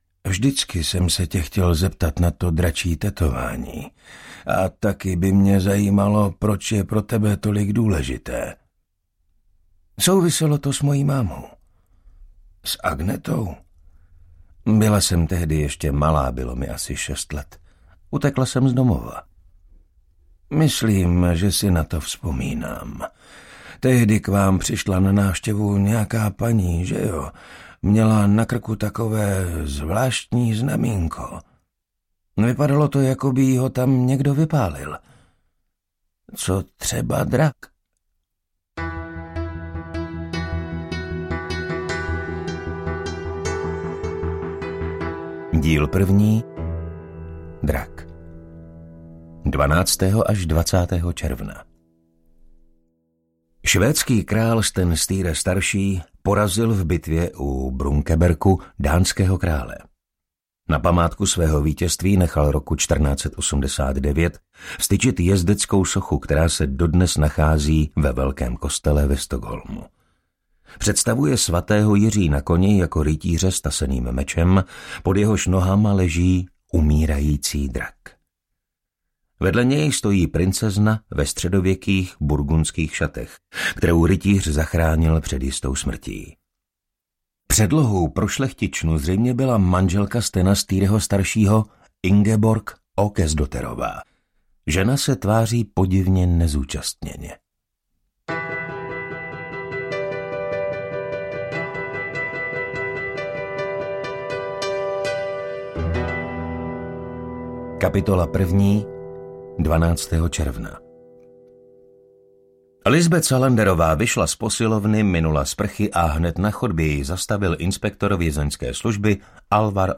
Muž, který hledal svůj stín - Milénium V audiokniha
Ukázka z knihy